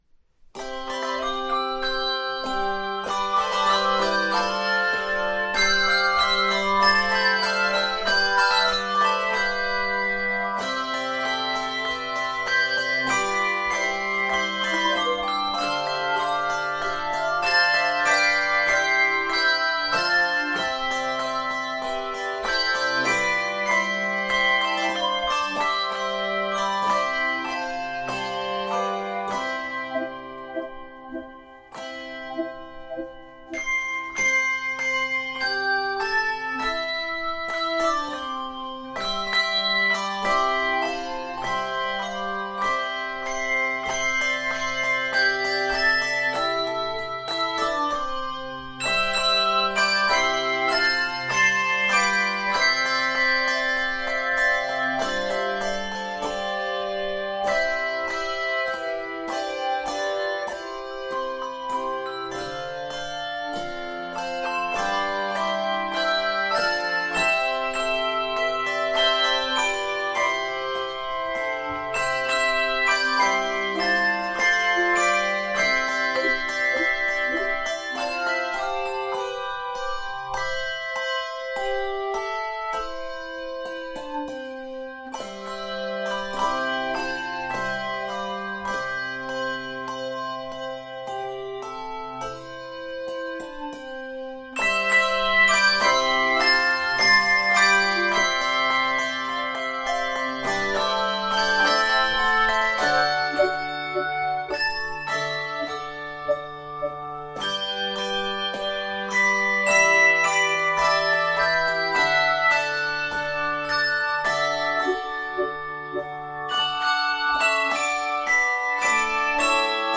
in the style of a spiritual